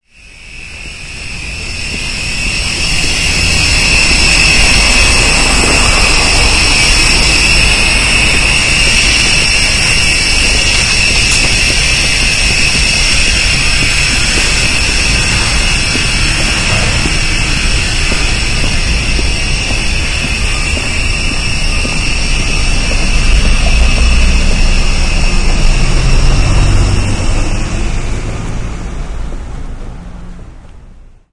城市" 尖叫声
描述：施工现场的声音。一个混凝土锯发出一些刺耳的声音。背景是汽车经过的细微声音。录制于韩国首尔。用Microtrack 24/96和驻极体话筒录制的。后来经过编辑和归一化。
Tag: 施工 现场录音 韩国 机械 首尔